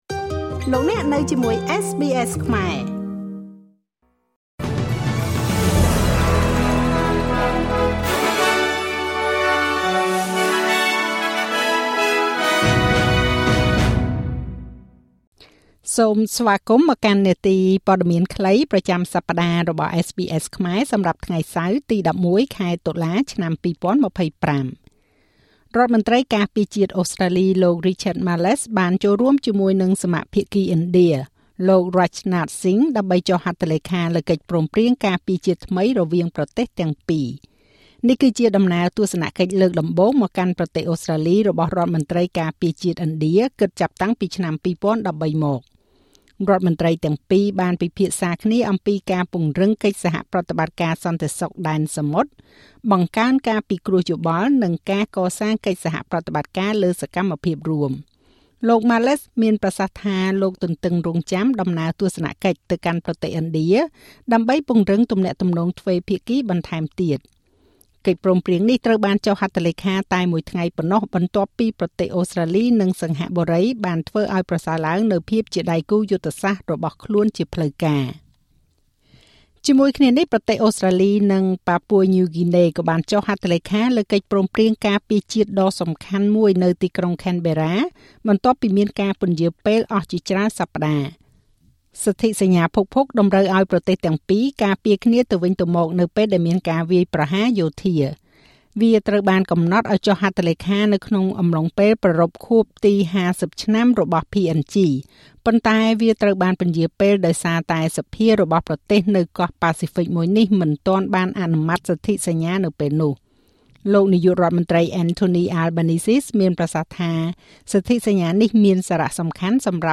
នាទីព័ត៌មានខ្លីប្រចាំសប្តាហ៍របស់SBSខ្មែរ សម្រាប់ថ្ងៃសៅរ៍ ទី១១ ខែតុលា ឆ្នាំ២០២៥